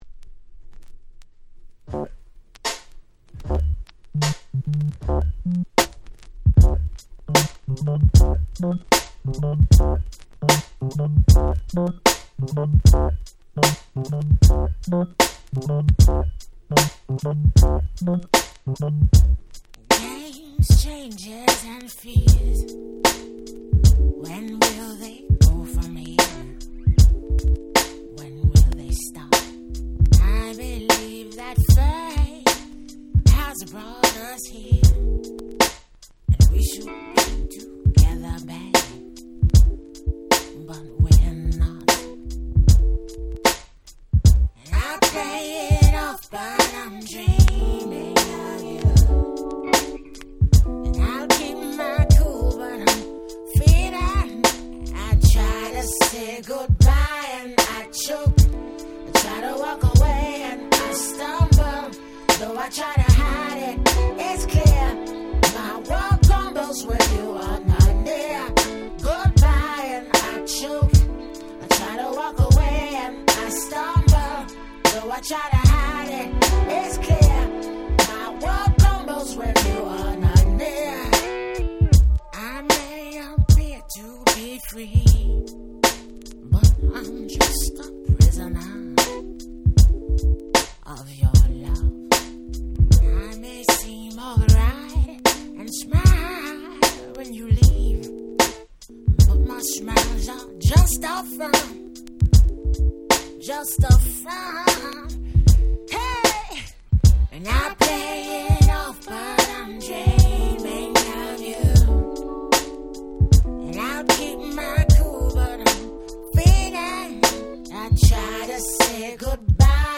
99’ Super Hit R&B/Neo Soul !!